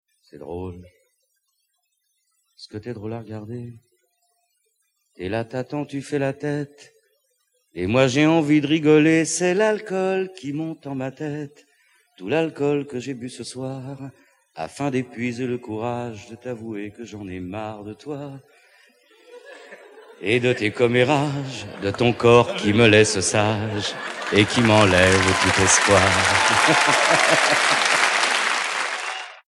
INEDITS SOLO TV/RADIO